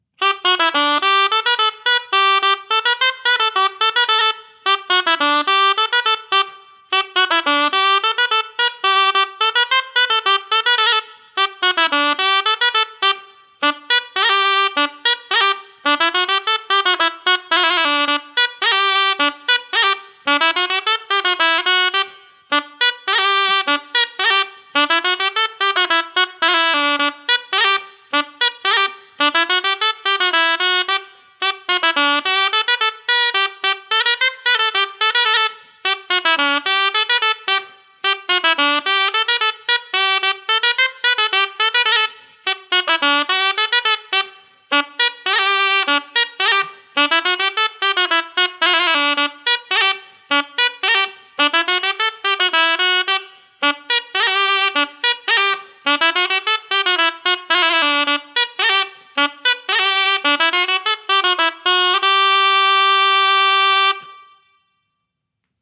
Lieu : Lamothe-Cassel
Genre : morceau instrumental
Instrument de musique : aboès
Danse : ramelet